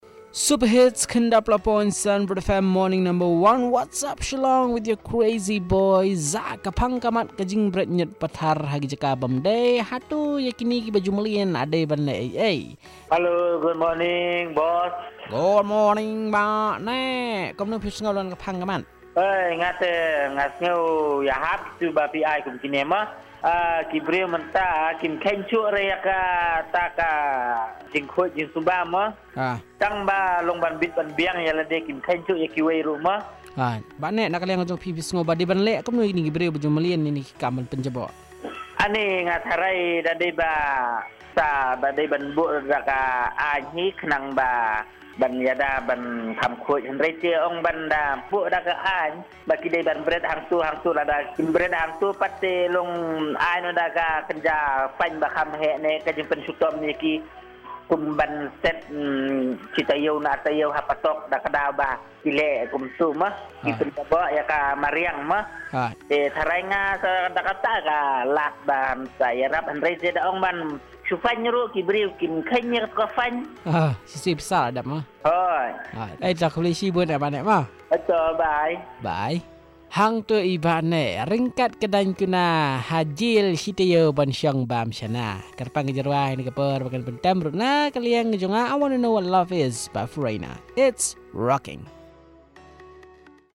Caller 2 on reckless throwing of garbage in the city